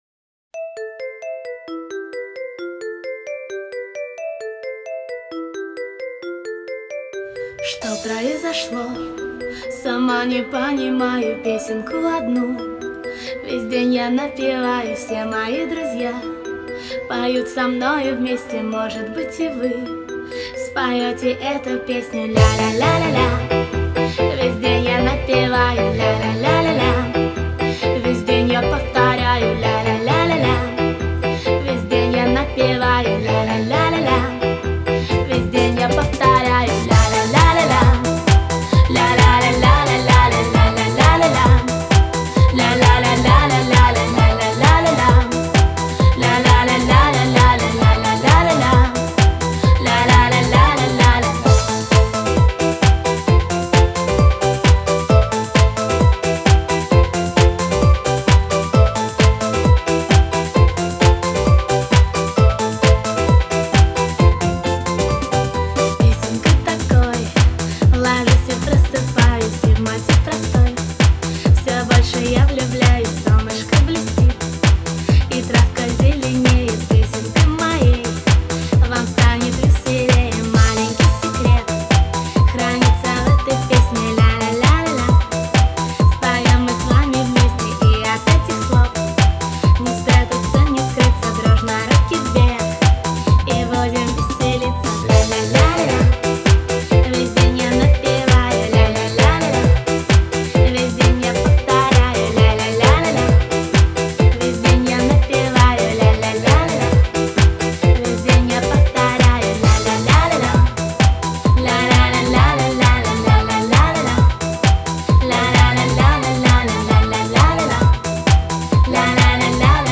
Трек размещён в разделе Русские песни / Танцевальная.